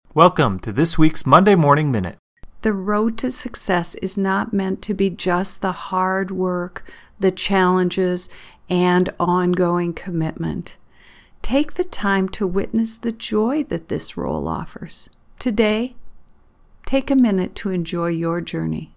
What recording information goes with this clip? Studio version: